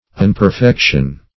Search Result for " unperfection" : The Collaborative International Dictionary of English v.0.48: Unperfection \Un`per*fec"tion\, n. Want of perfection; imperfection.
unperfection.mp3